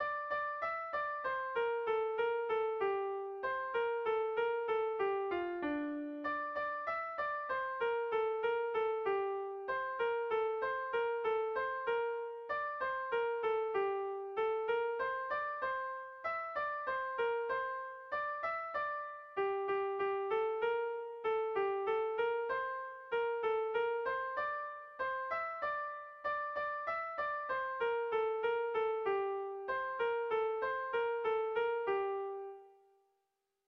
AABDEA